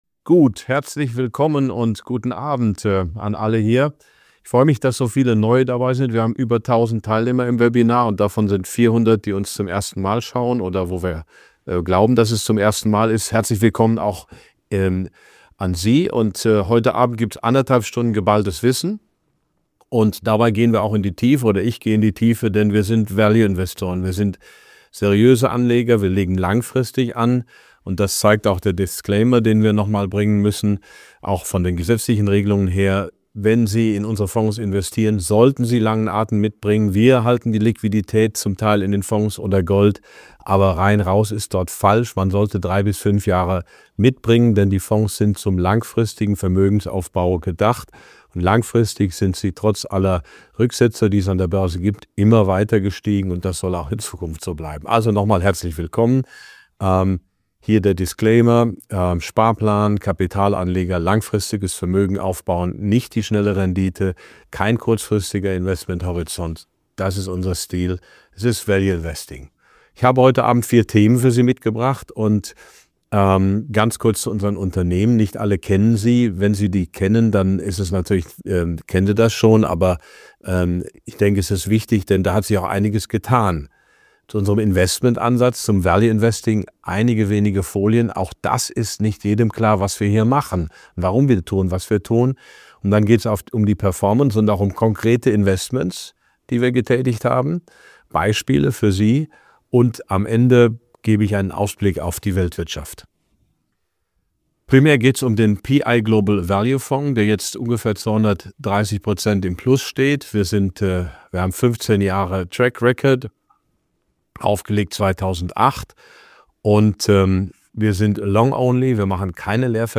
Beschreibung vor 1 Jahr Prof. Dr. Max Otte gibt im exklusiven Webinar einen Einblick in seine Investmentstrategie und die aktuelle Entwicklung seiner Fonds. Er erklärt die Grundprinzipien des Value Investing und analysiert die Performance seines Portfolios mit Berkshire Hathaway als Schlüsselposition.